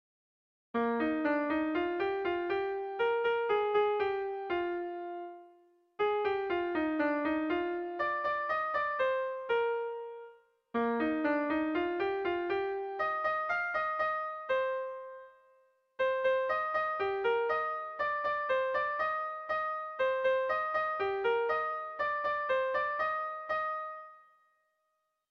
Dantzakoa
Zortziko txikia (hg) / Lau puntuko txikia (ip)